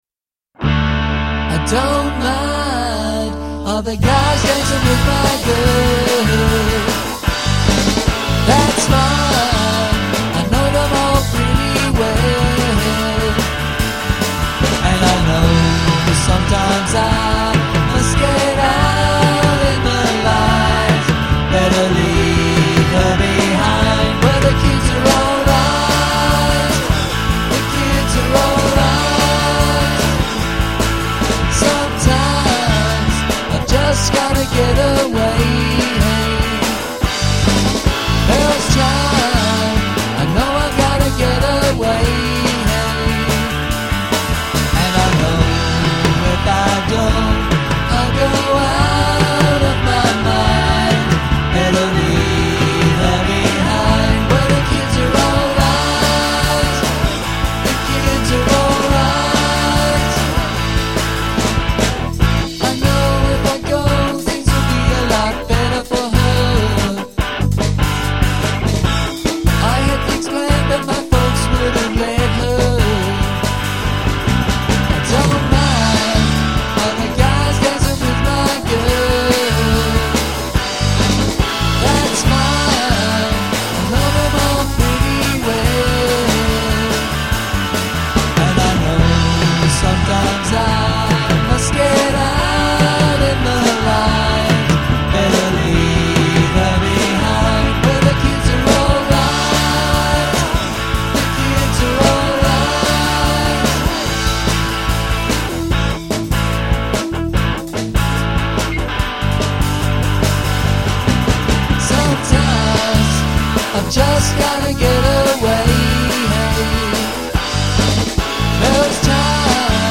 classic 60s covers
recorded live at ABC Hobart’s Studio 701 in 2007